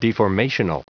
Prononciation du mot deformational en anglais (fichier audio)